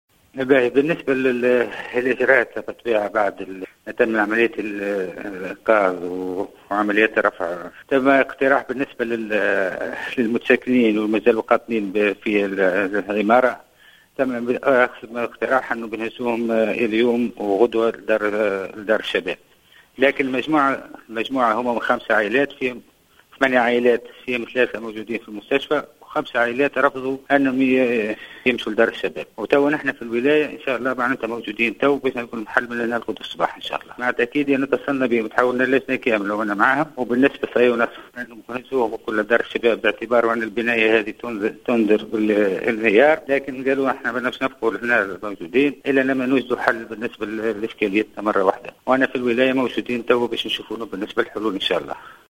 وأبرز الديناري، في تصريح للجوهرة أف أم، أنه توجيه اقتراح للمتساكنين القاطنين في العمارة المجاورة الآيلة للسقوط، بإيوائهم في دار الشباب، خلال هذه الليلة والليلة القادمة، إلا أن هذا الاقترح جوبه بالرفض.